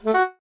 jingles-saxophone_03.ogg